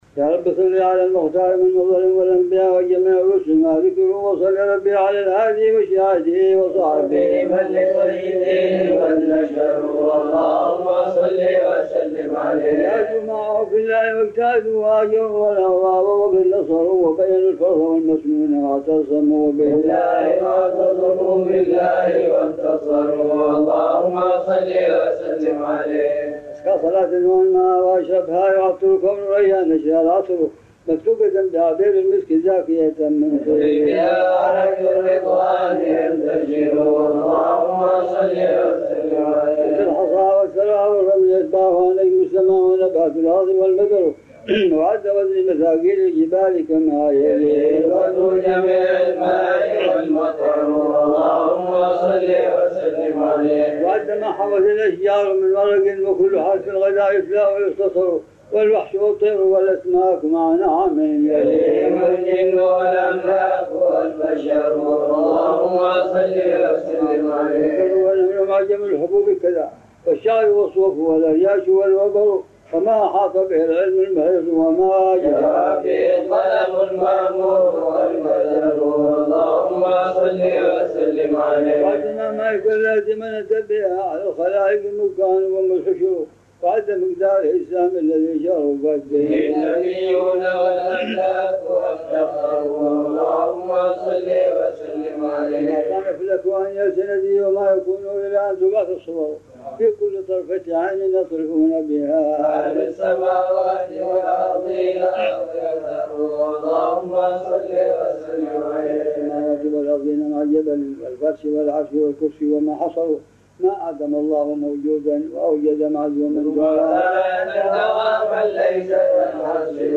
Salaat al - Mudariyya of Imam al- Busiri (reciited